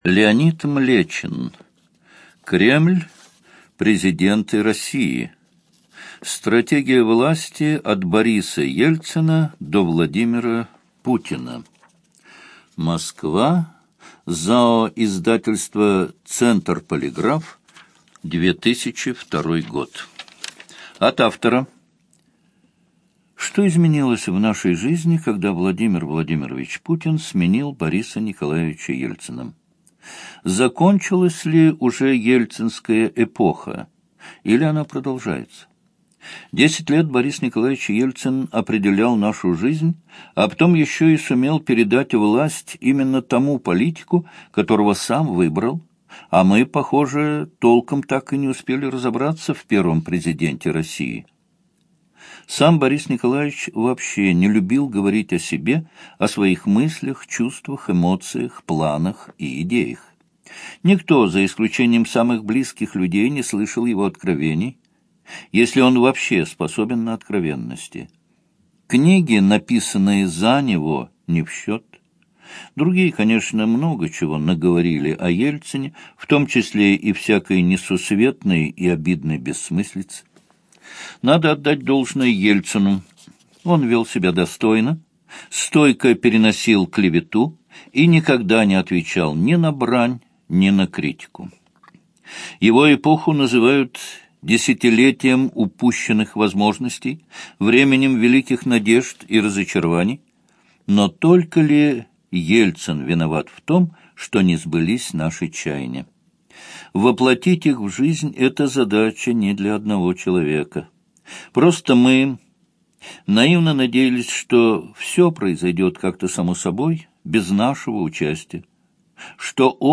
ЖанрПублицистика
Студия звукозаписиЛогосвос